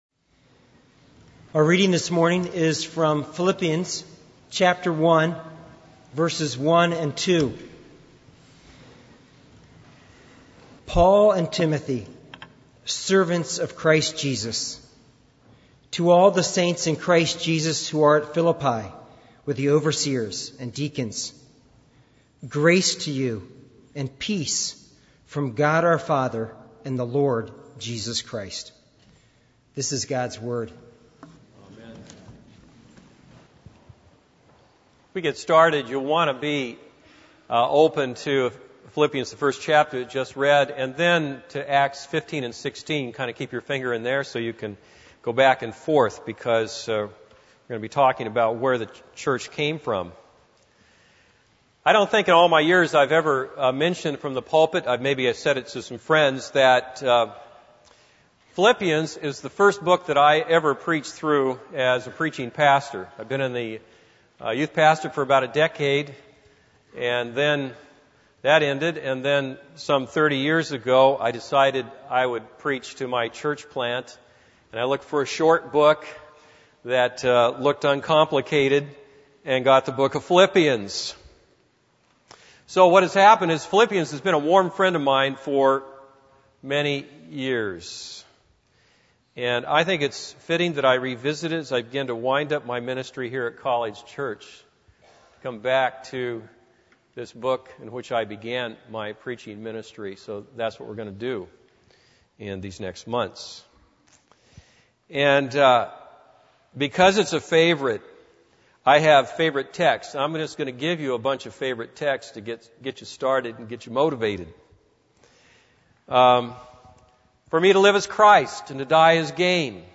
This is a sermon on Philippians 1:1-2.